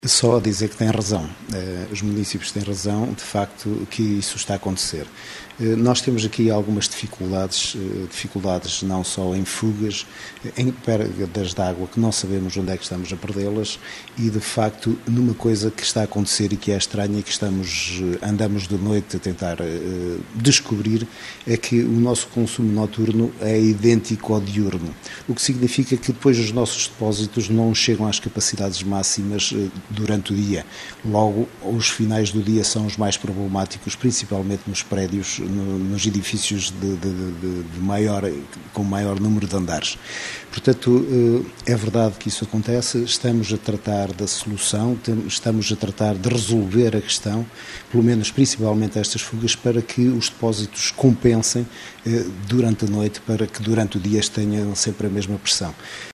Duarte Moreno, o autarca local, admite que os munícipes têm razão, e deixa alguma explicações.